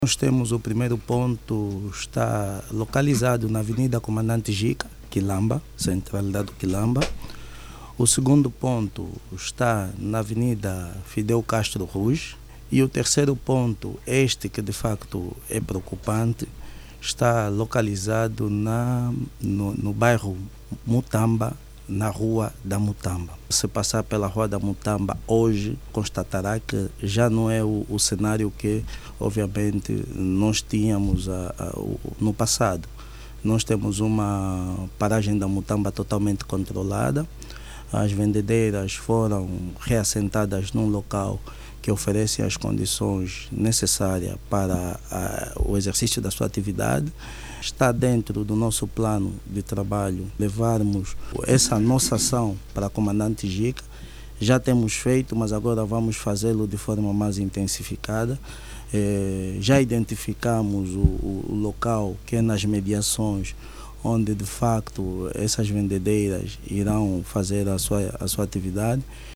A administração Kilamba, tem três pontos de constrangimento e que vai ganhando nova imagem com o reordenamento do comércio interno, segundo o seu Administrador Adjunto para Área Técnica, Garibaldino Costantino.